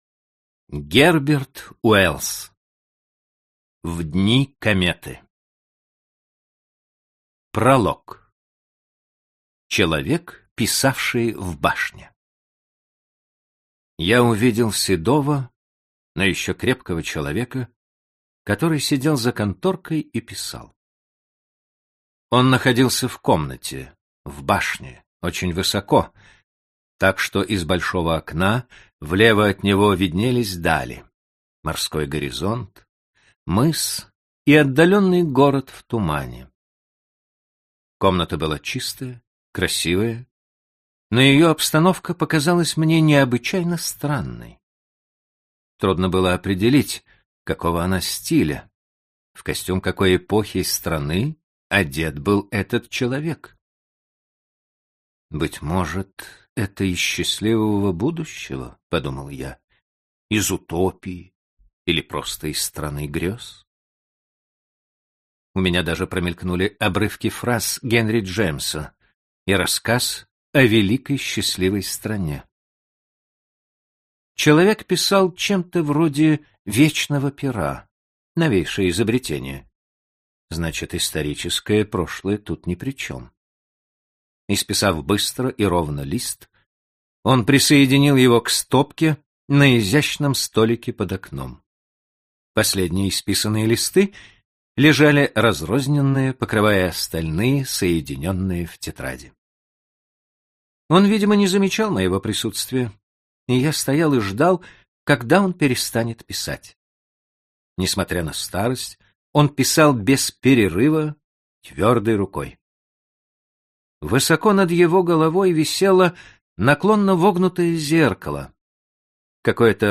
Аудиокнига В дни кометы | Библиотека аудиокниг